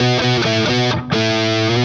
Index of /musicradar/80s-heat-samples/130bpm
AM_HeroGuitar_130-B01.wav